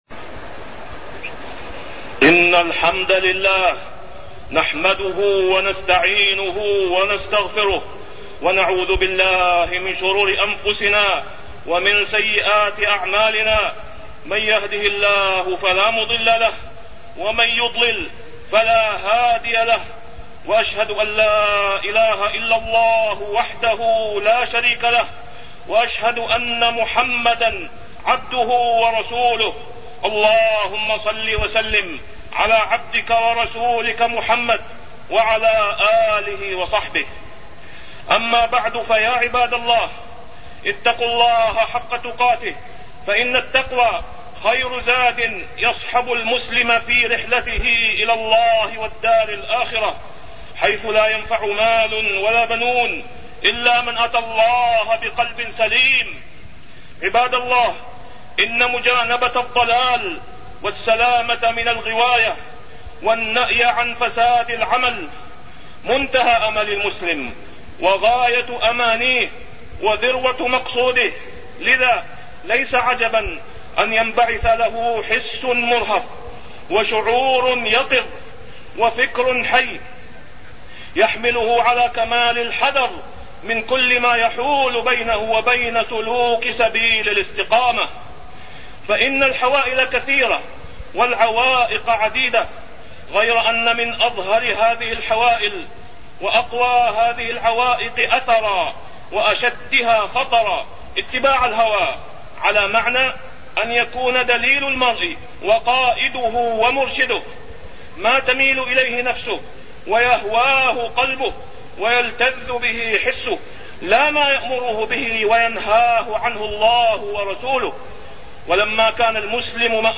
تاريخ النشر ٢١ صفر ١٤٢٢ هـ المكان: المسجد الحرام الشيخ: فضيلة الشيخ د. أسامة بن عبدالله خياط فضيلة الشيخ د. أسامة بن عبدالله خياط إتباع الهوى